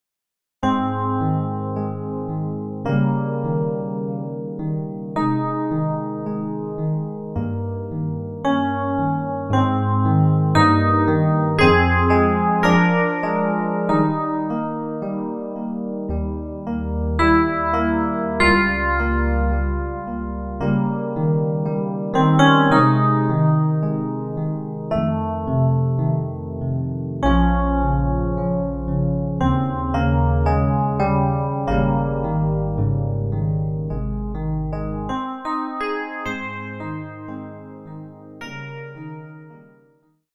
sonivox_dvi-fmpiano.mp3